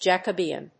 音節Jac・o・be・an 発音記号・読み方
/dʒ`ækəbíːən(米国英語), ˌdʒækʌˈbi:ʌn(英国英語)/